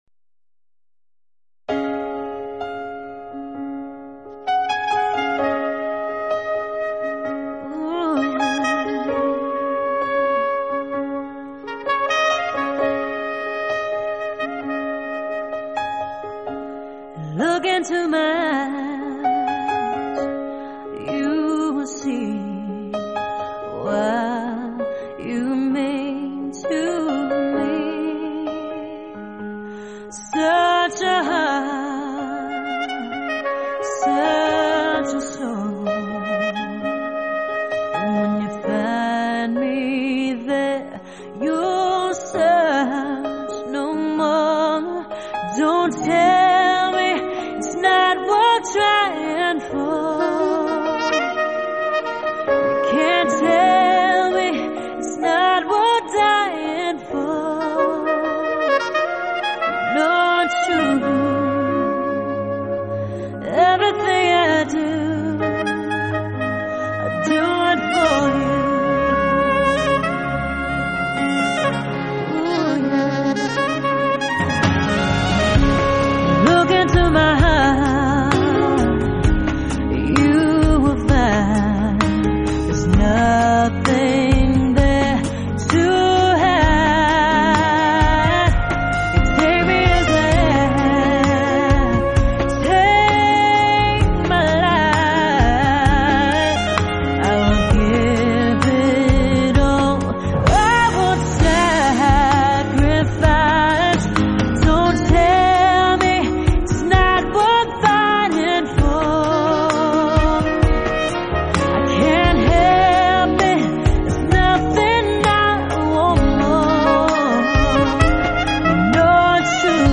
音乐风格:乐器演奏
聆赏缠绵悱恻的曼妙之音
专辑运用SACD录音技术，采用顶级SADIE DSD Series 5 数位混音作后期处理
浪漫迷人的萨克斯风伴随弦乐以及煽情的人声和鸣，令浪漫节奏与爱情的律动